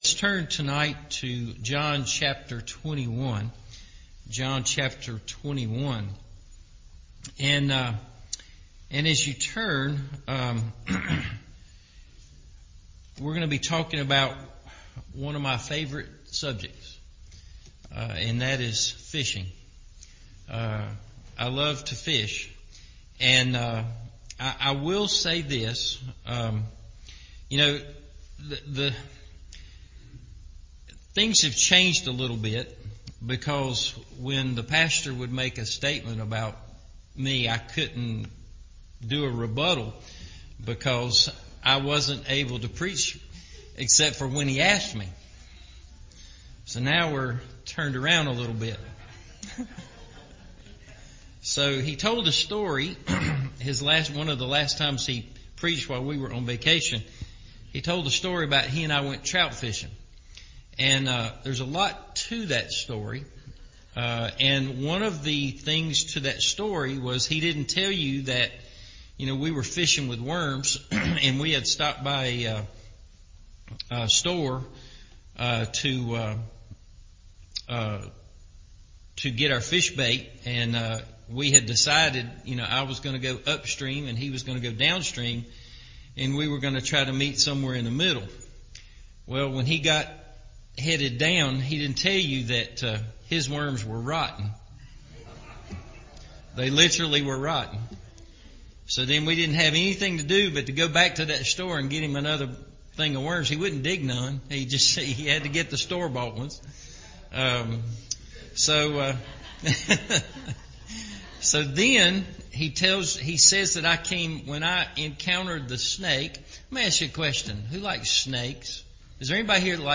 From Failure To Success – Evening Service